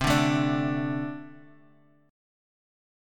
D5/C chord
D-5th-C-x,3,0,2,3,x.m4a